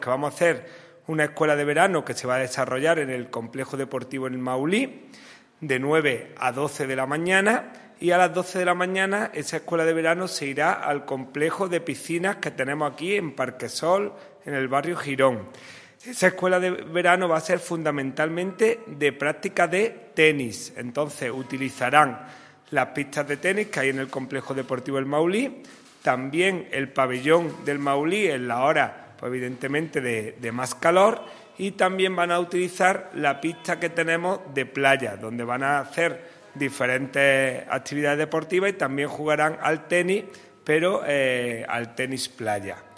El teniente de alcalde delegado de Deportes, Juan Rosas, ha presentado hoy en rueda de prensa las Escuelas Deportivas de Verano 2022, iniciativa que promueve el Área de Deportes del Ayuntamiento de Antequera con el objetivo de facilitar la conciliación familiar y laboral durante el mes de julio, época ya de vacaciones en los colegios.
Cortes de voz